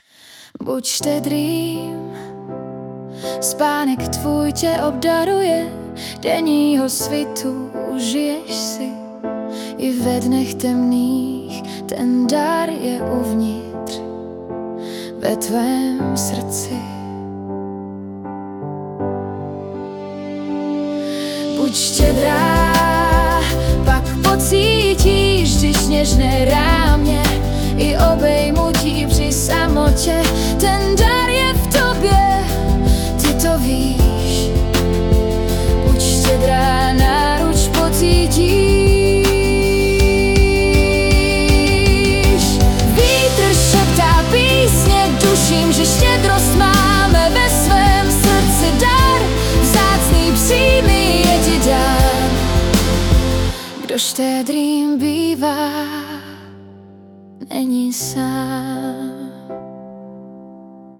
Hudba a zpěv AI.
/Maličko šišlá :D. Tak komu AI zhudebnění nevadí, poslechněte si/
... po takovém pomalejším začátku... AI ten dar tady rytmicky rozbaluje*